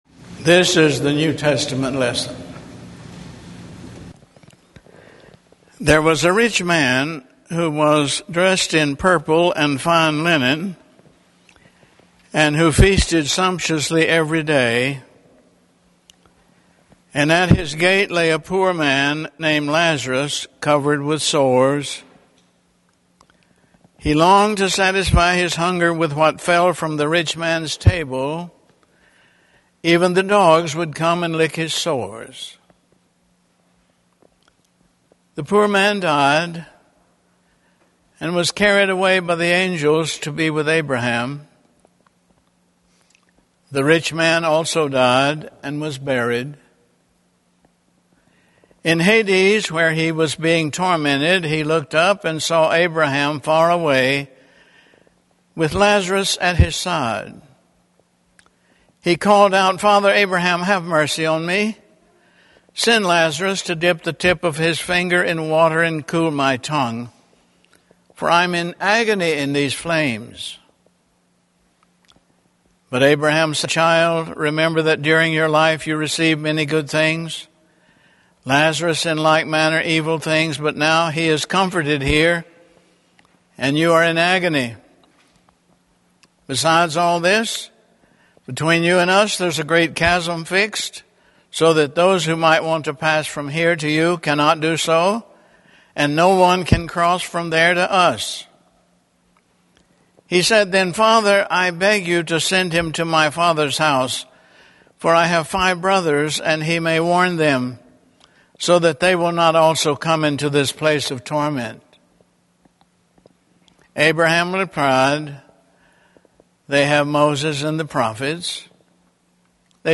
Sermon Archive 2010 | Morningside Presbyterian Church